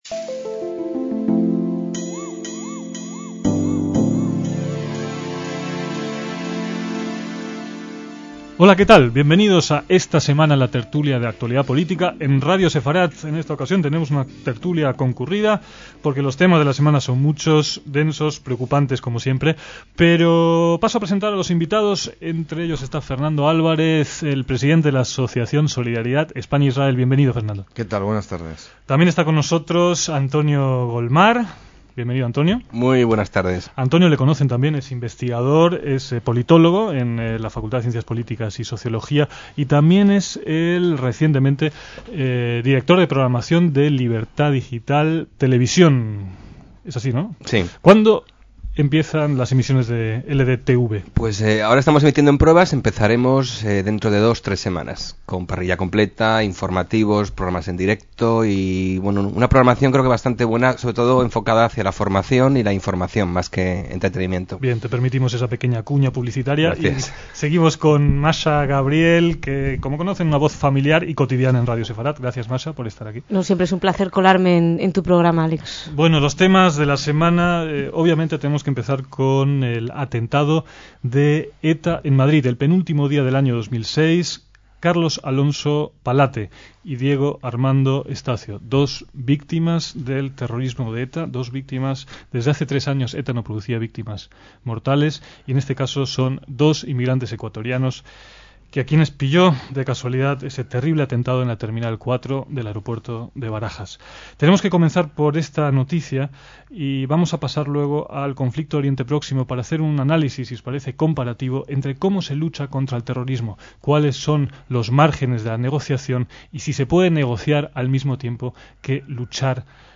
tertulia semanal